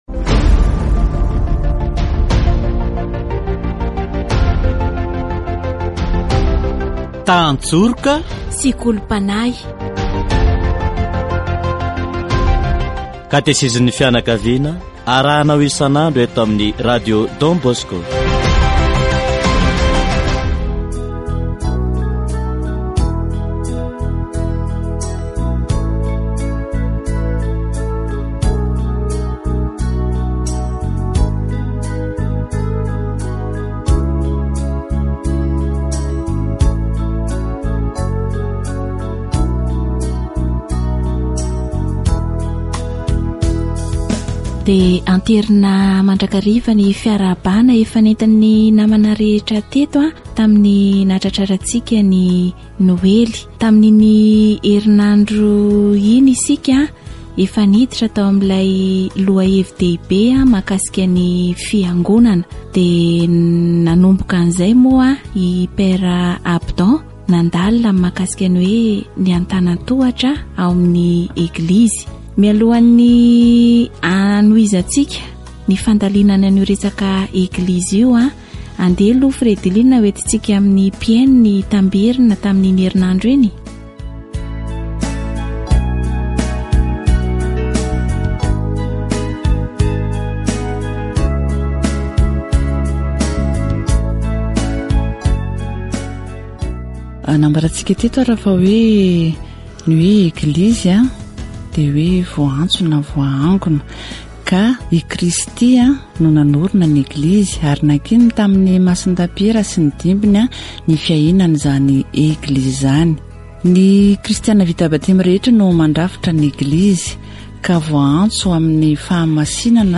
Catéchèse sur la communion des baptisés